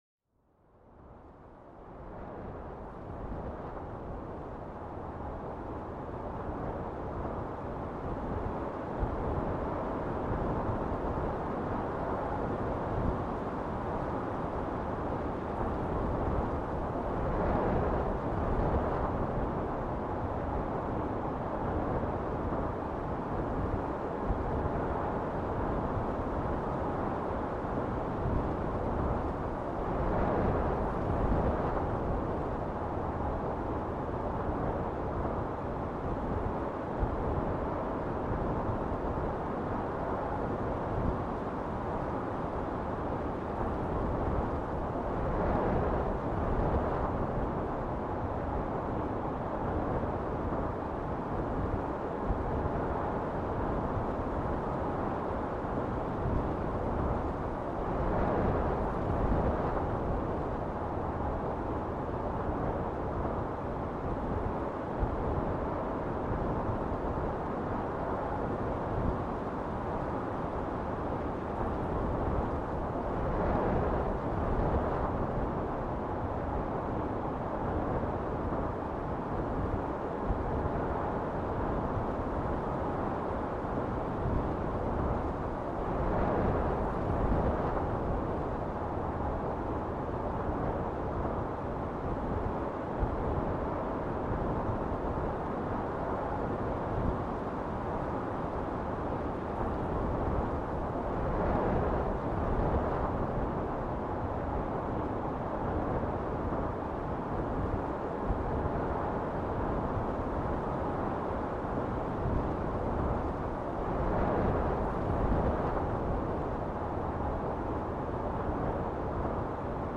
Découvrez la mélodie harmonieuse du vent, une symphonie naturelle qui apaise l’âme et revitalise l’esprit. Cet épisode vous emporte dans un voyage auditif où chaque rafale raconte une histoire, offrant un moment de sérénité inégalée.
Idéal pour la relaxation, la méditation, ou comme fond sonore pour s’endormir, il est votre sanctuaire auditif.